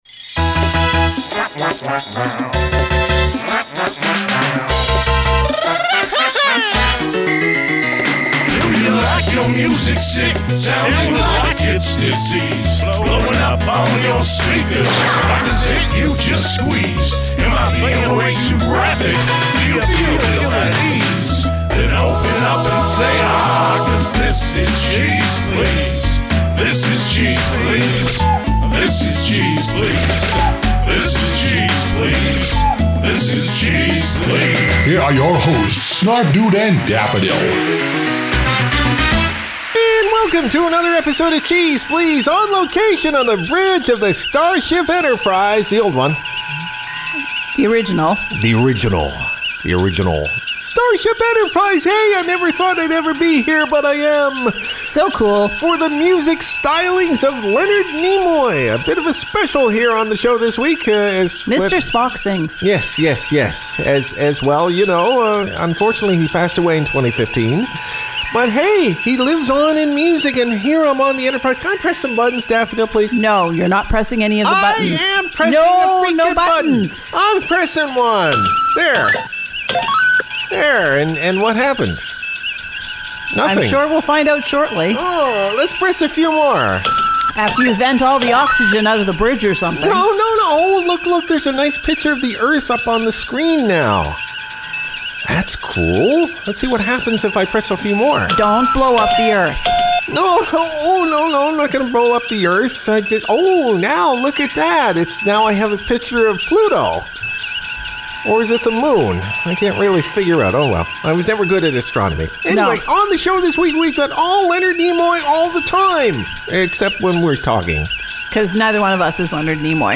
From the bridge of the Starship Enterprise, our hosts present a tribute to the music stylings....